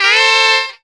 HARM RIFF.wav